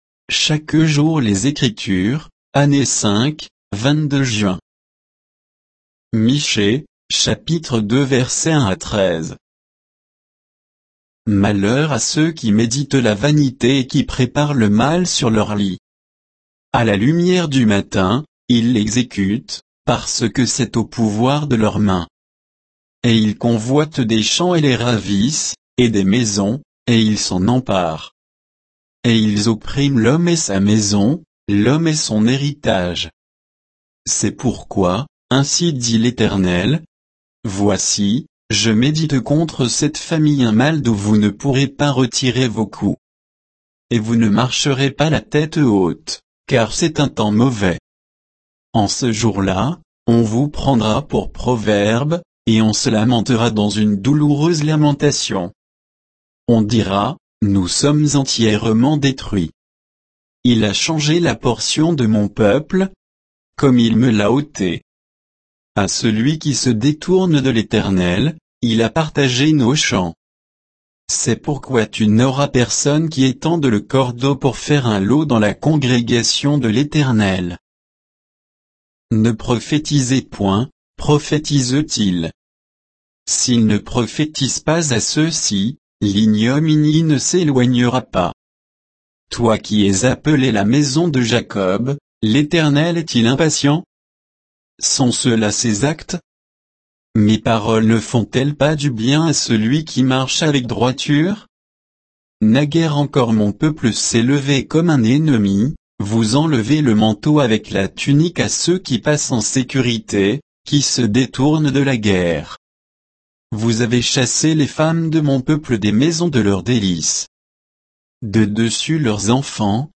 Méditation quoditienne de Chaque jour les Écritures sur Michée 2